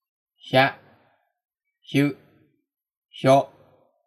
ひゃhya ひゅhyu ひょhyo